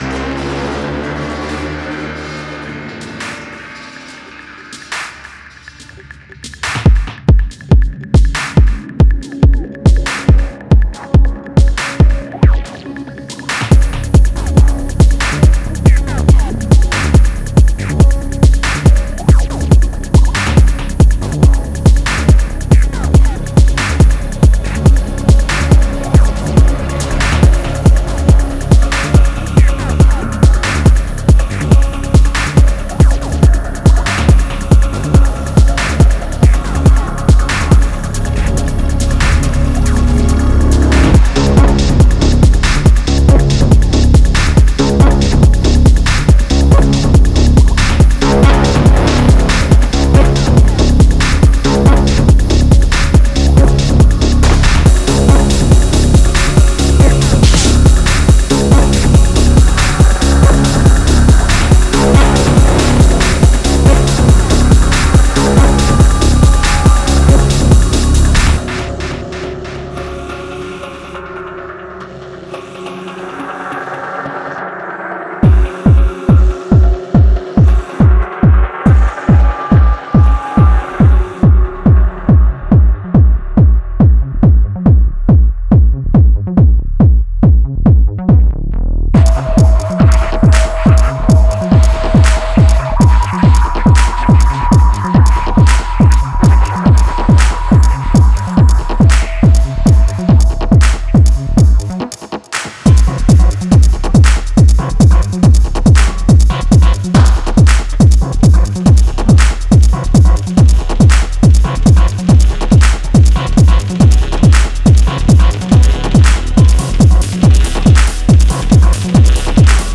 テクノ系では特に使い勝手も良く、 重宝するサンプルパックです。
Genre:Techno
このパックには、破壊力抜群のドラムパートと、キレのあるドラムワンショットが大量に収録されています。